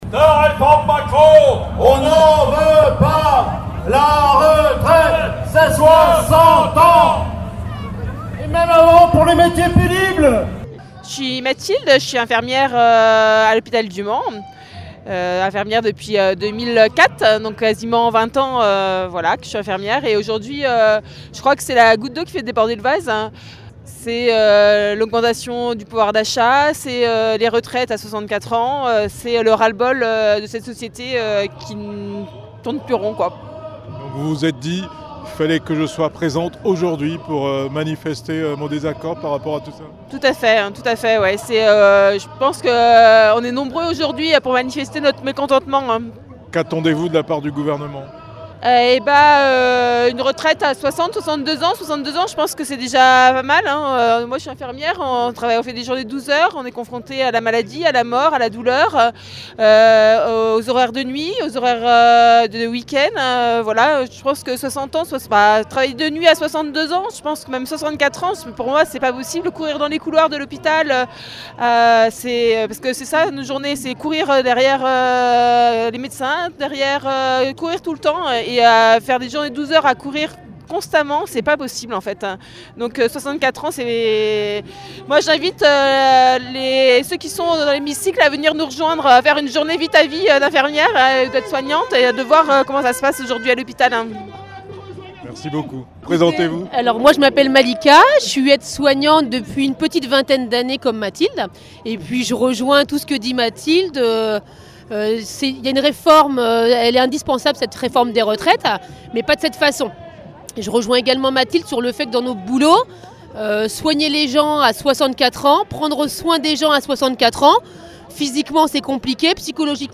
Manifestation contre la réforme des retraites au Mans